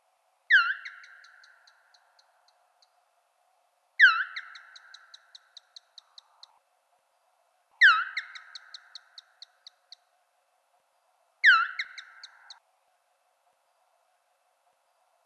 Hier sind Fledermauskontaktrufe zu hören.